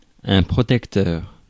Ääntäminen
IPA: /pʁɔ.tɛk.tœʁ/